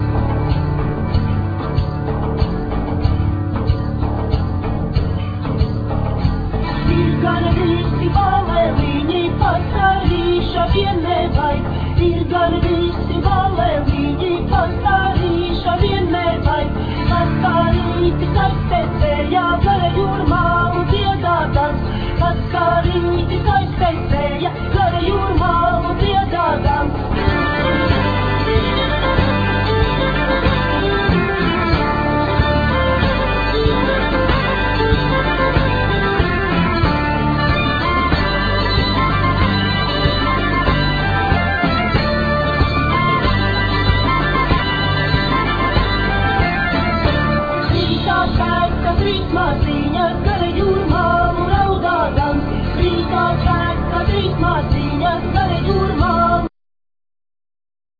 Vocal,Violin
Vocal,Kokle,Bagpipe,Acordeon
Vocal,Bass,Giga
Vocal,Guitar
Percussions